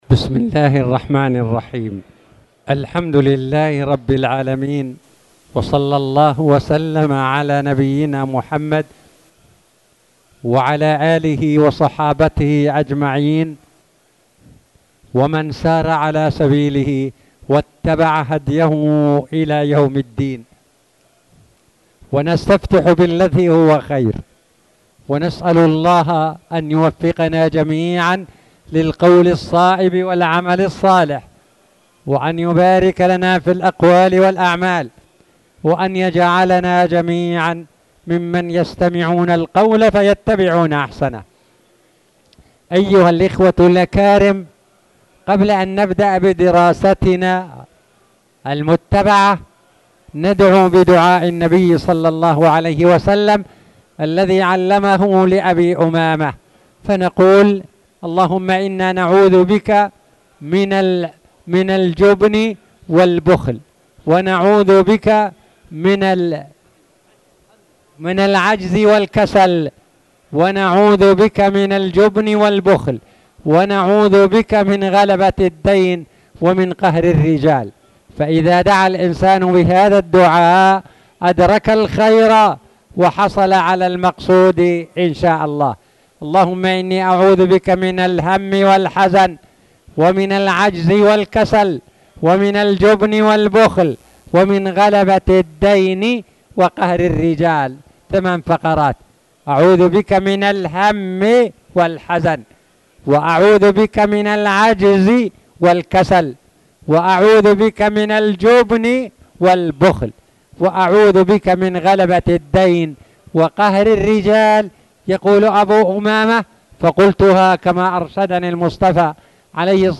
تاريخ النشر ١٢ شعبان ١٤٣٨ هـ المكان: المسجد الحرام الشيخ